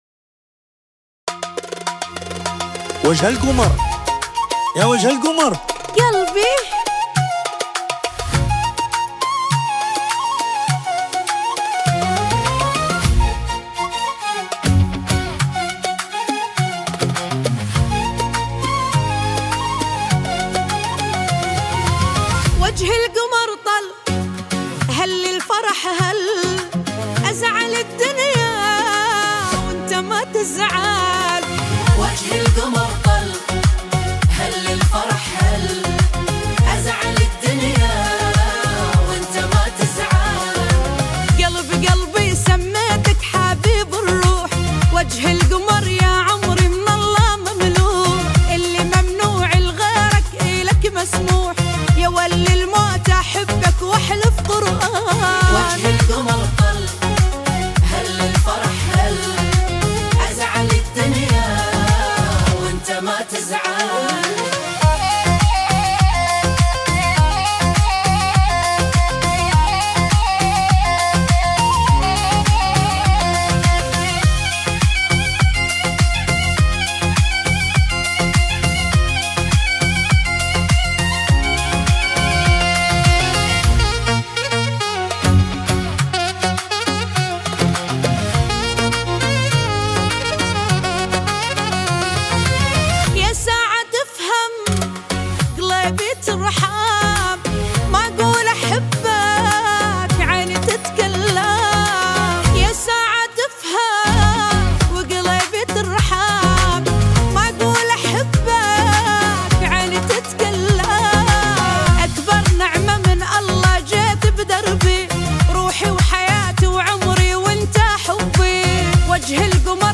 • اغاني رومانسية عربية
صوت قوي + تمثيل + قصة حب + توقيت مثالي